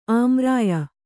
♪ āmrāya